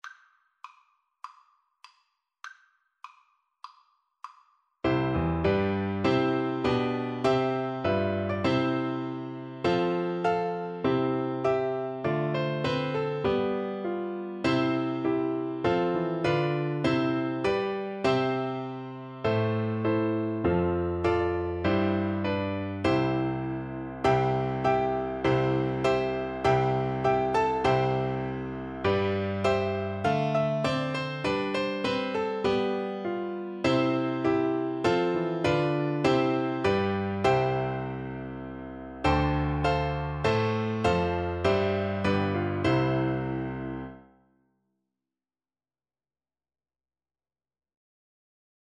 4/4 (View more 4/4 Music)
Andante